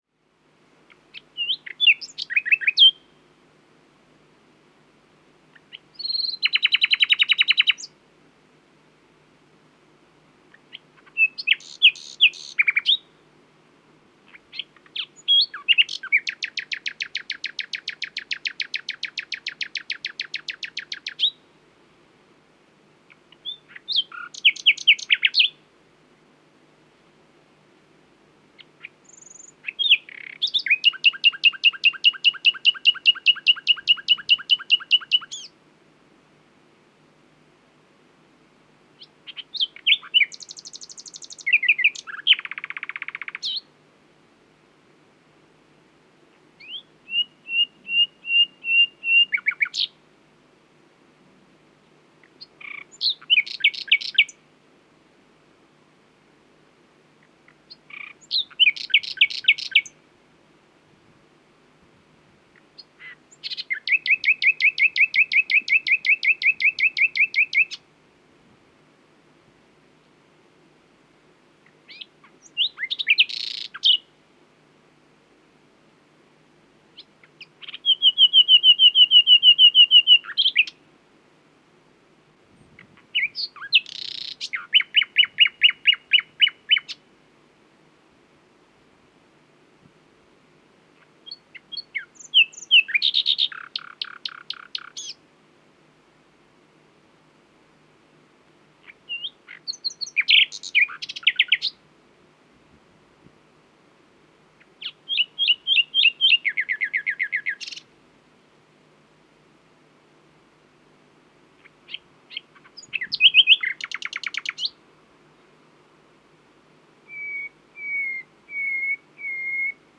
수컷
데번, 잉글랜드에서 녹음된 노래
노래는 크고, 인상적인 범위의 휘파람, 떨림, 콸콸거림을 포함한다.